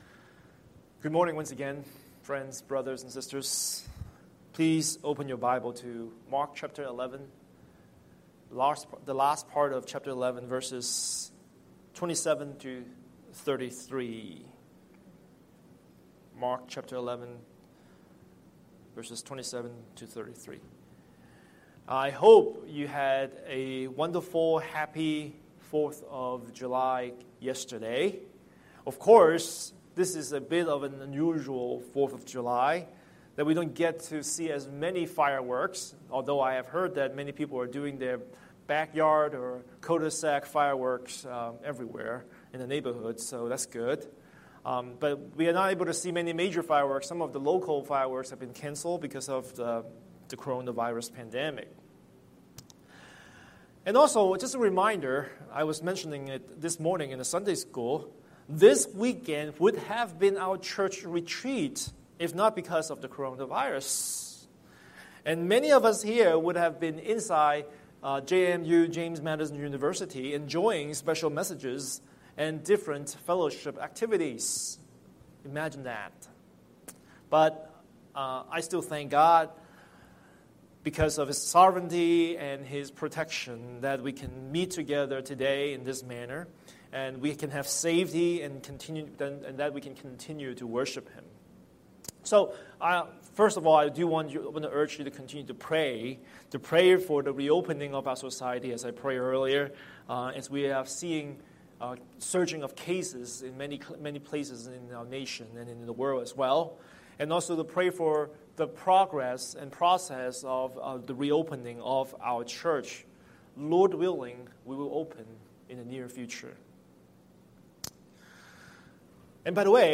Scripture: Mark 11:27–33 Series: Sunday Sermon